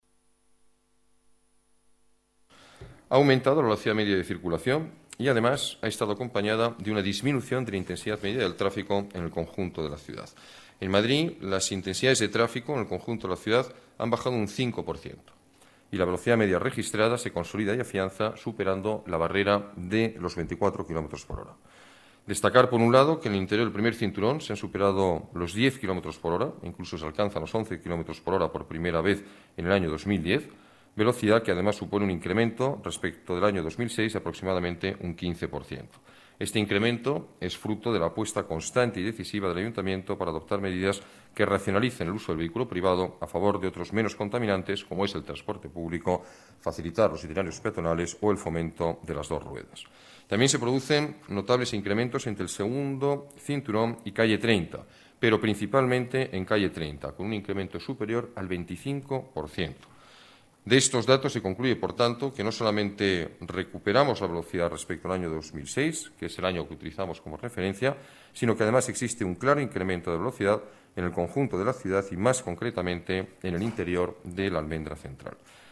Nueva ventana:Declaraciones del alcalde de Madrid, Alberto Ruiz-Gallardón